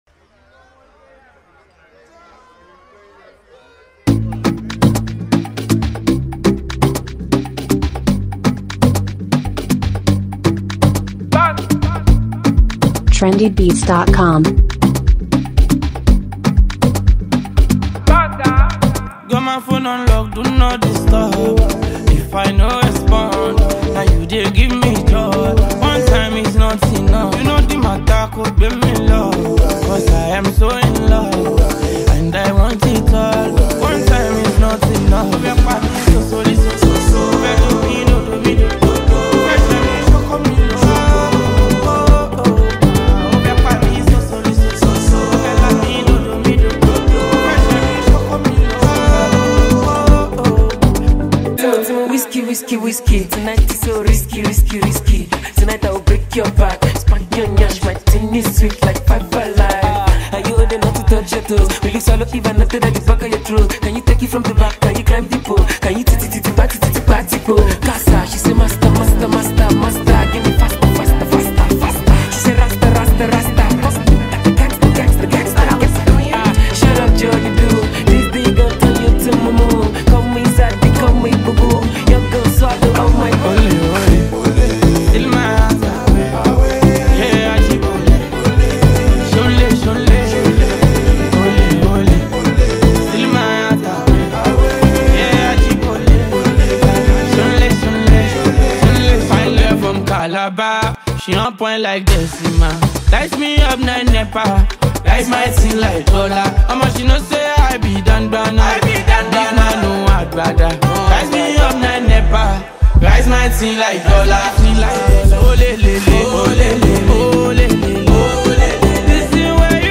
which fuses afro beats and amapiano